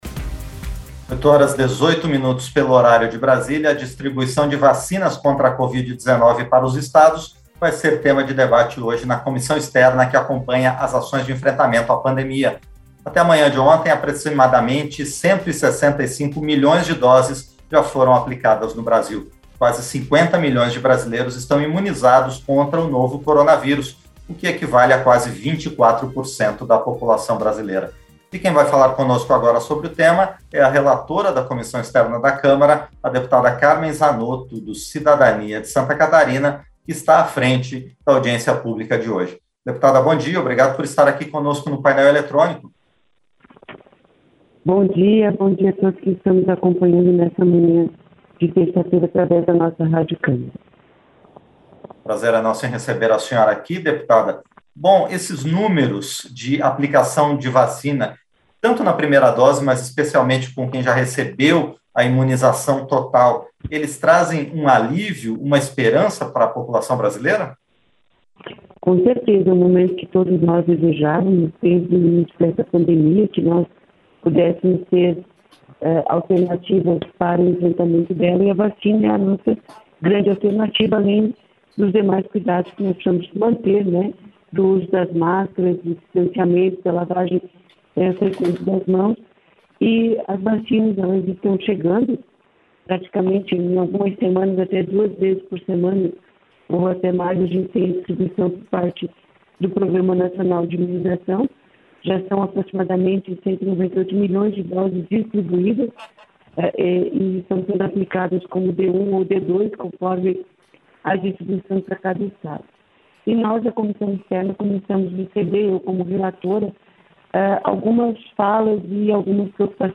Entrevista - Dep. Carmen Zanotto (CID- SC)